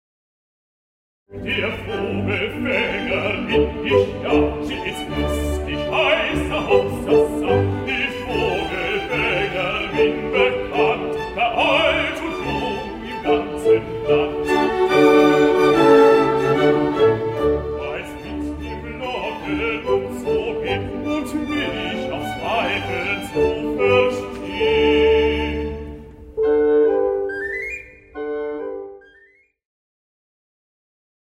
papa baryton.2.mp3